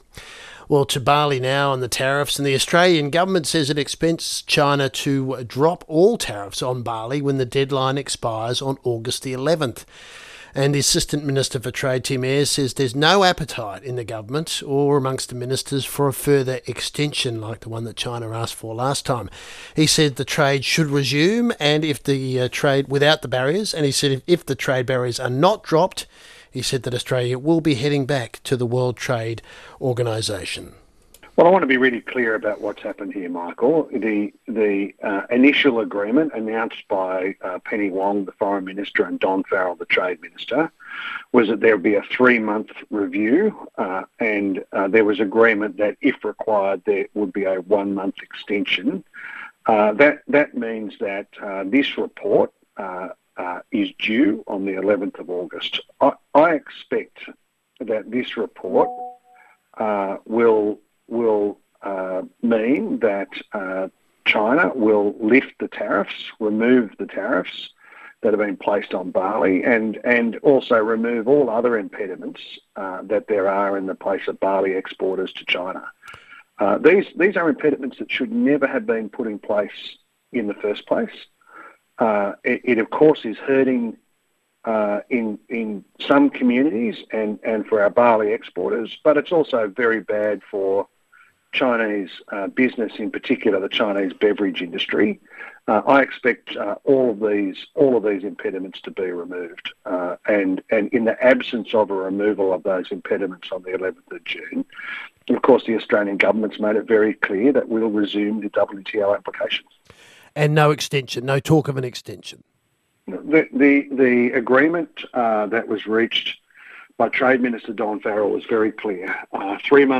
This followed on from an interview with Assistant Minister for Trade, Tim Ayres, about the situation.